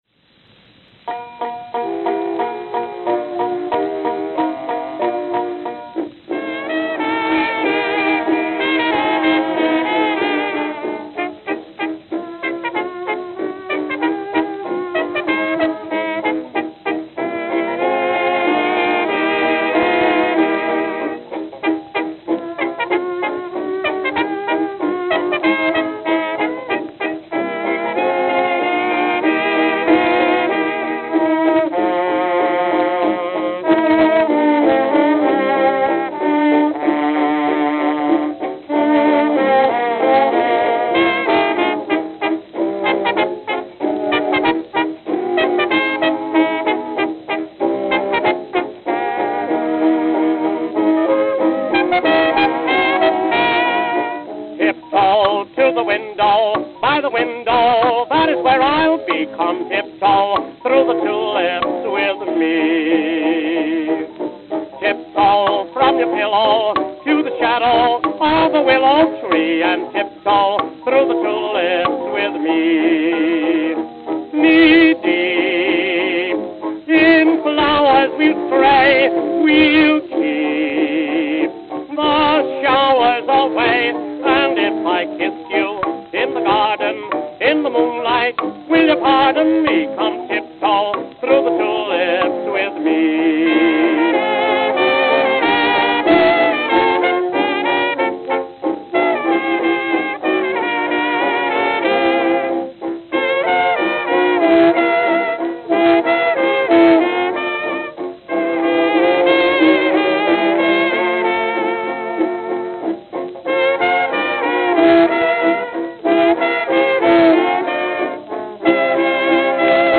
Acoustic Recordings